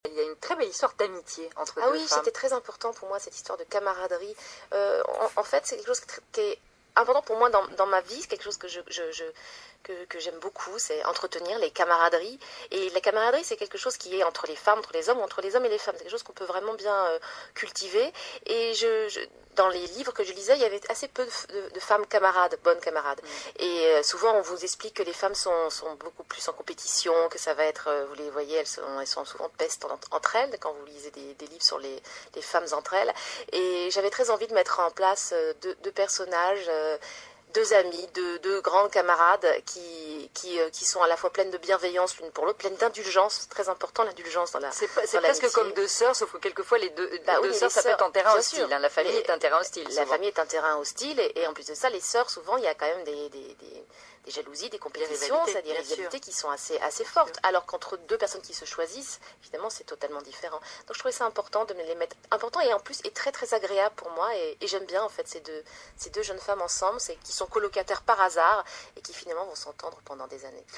En fait, ce qui le gênait, c’était les répétitions, les hésitations, les « changements de direction » en cours de phrase, bref, tout ce qui caractérise l’oral.
Véronique Ovaldé, qui est écrivain, parle vite, virevolte d’un mot à l’autre mais qui lui reprocherait de mal s’exprimer ?